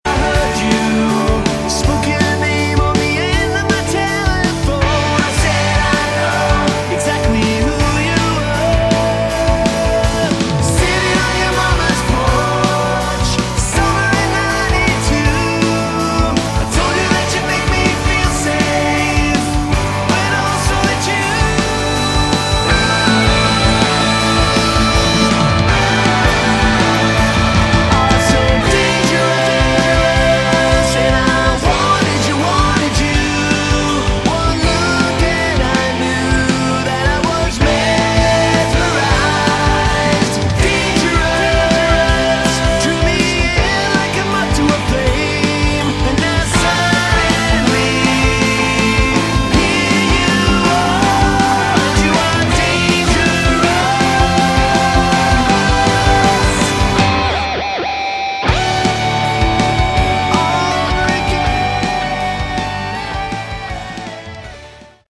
Category: Melodic Hard Rock
vocals
bass
guitars
keyboards
drums